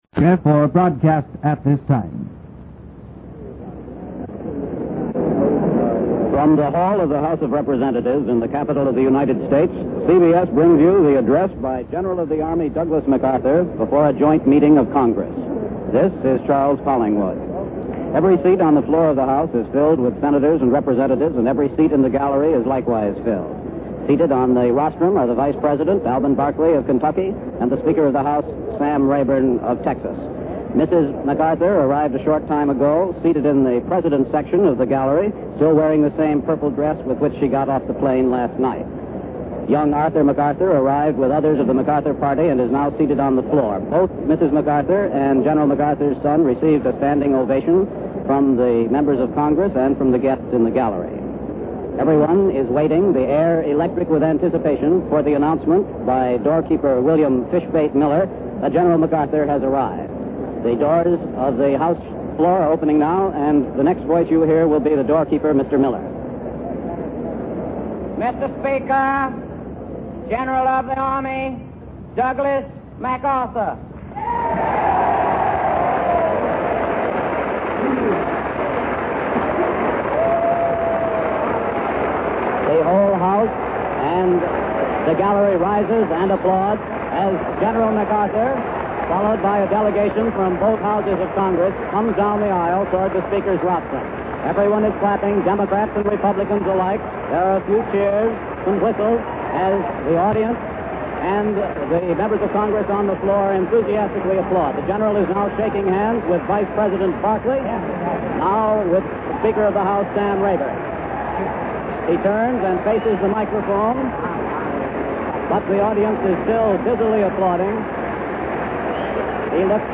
On the blog I talk about General Douglas MacArthur and the speech he gave on April 19, 1951.